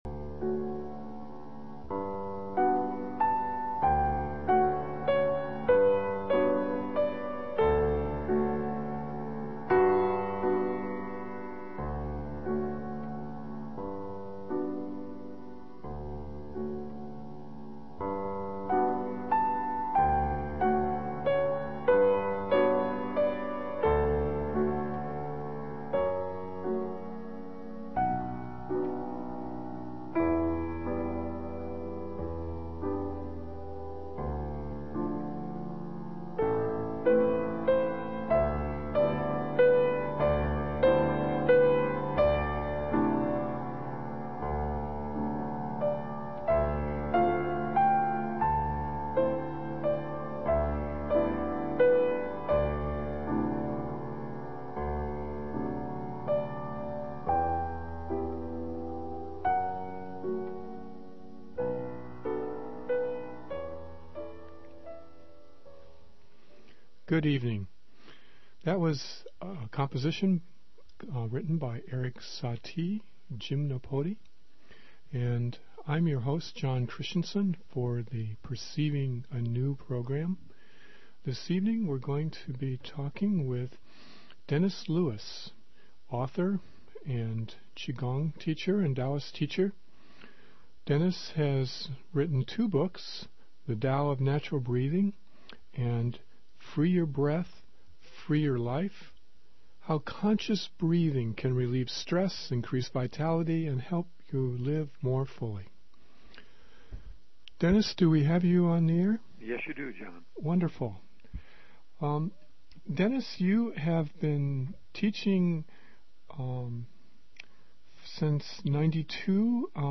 Kmud-Interview.mp3